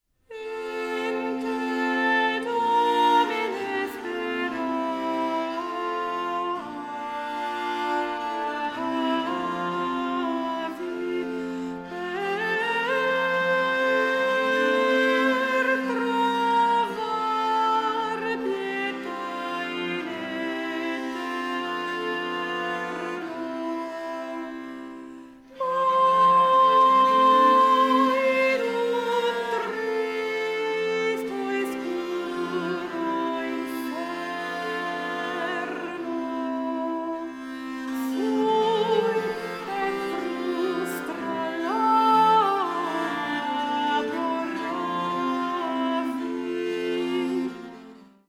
24 bit stereo
soprano
Rose Consort of Viols
Recorded 26-28 November 2014 in the Great Hall of Forde Abbey, Somerset, UK